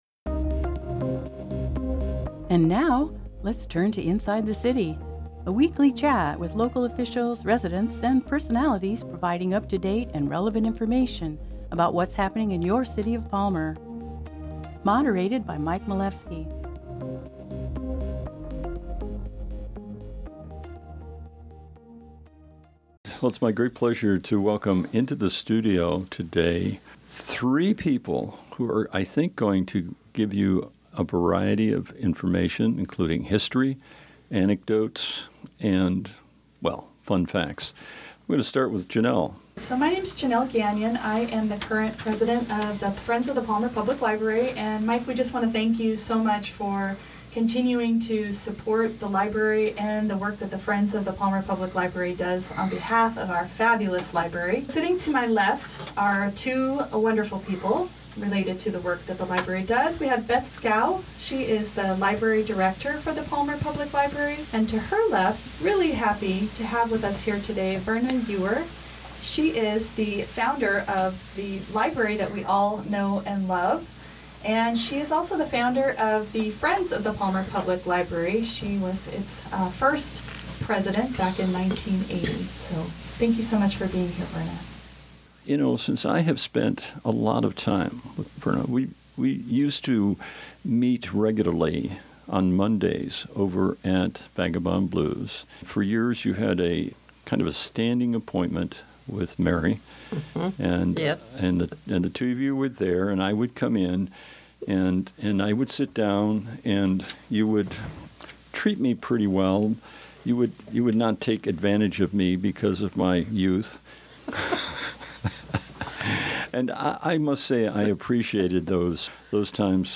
Discussions and interviews with employees and administrators from the city of Palmer Download file